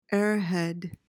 PRONUNCIATION: (AIR-hed) MEANING: noun: 1.